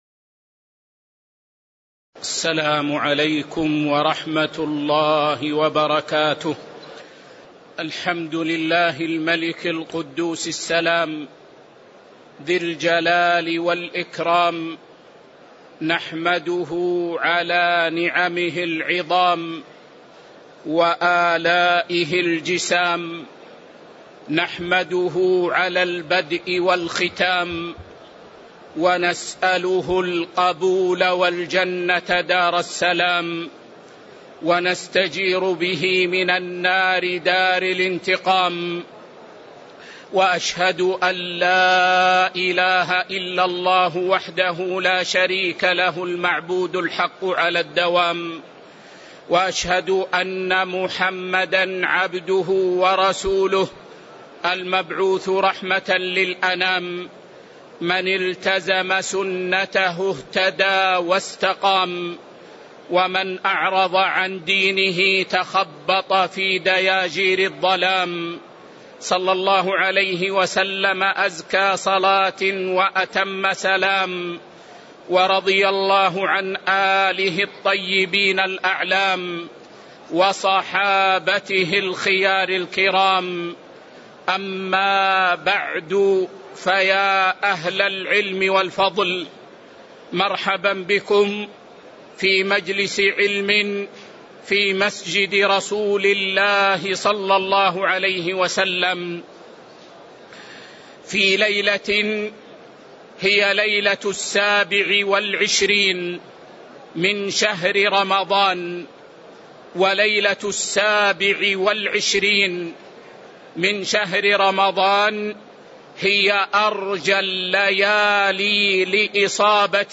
تاريخ النشر ٢٦ رمضان ١٤٤٣ هـ المكان: المسجد النبوي الشيخ